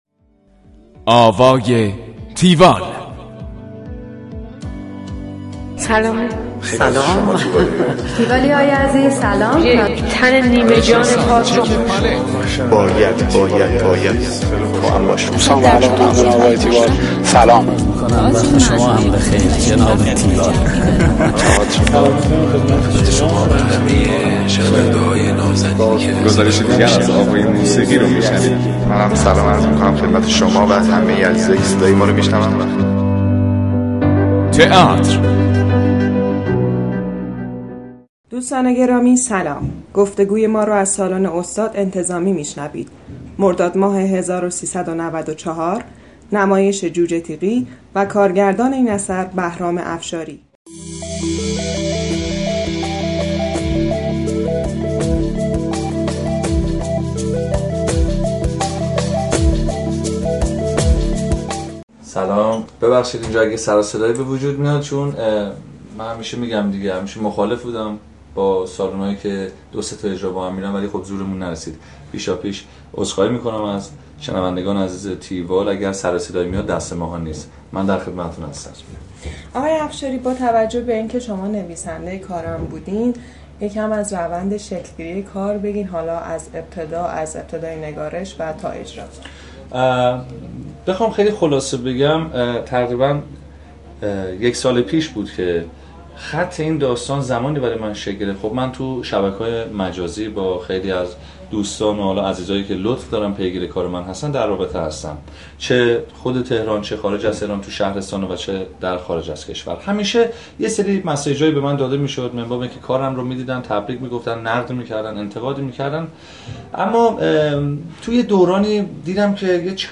گفتگوی تیوال با بهرام افشاری
گفتگوی تیوال با بهرام افشاری / نویسنده، کارگردان و بازیگر.
tiwall-interview-bahramafshari.mp3